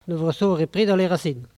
Localisation Saint-Urbain
Catégorie Locution